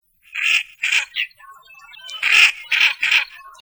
milouin.mp3